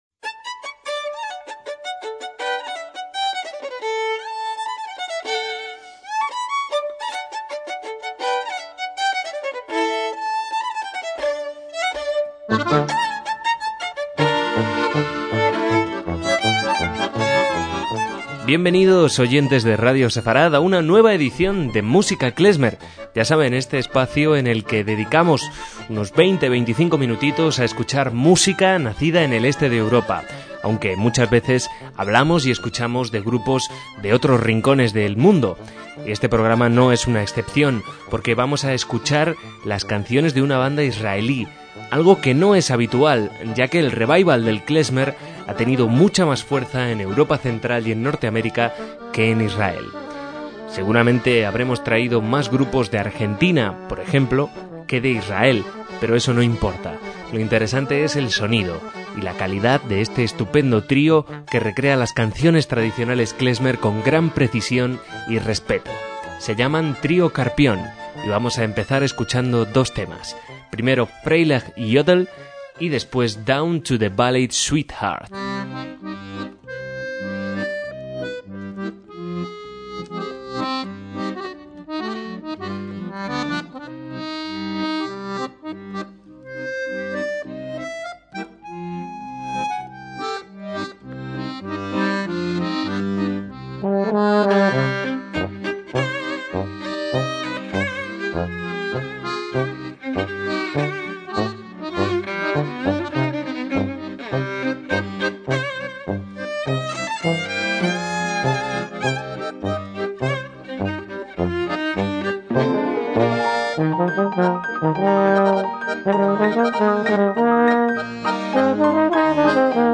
MÚSICA KLEZMER
acordeón, piano y voz
violín y voz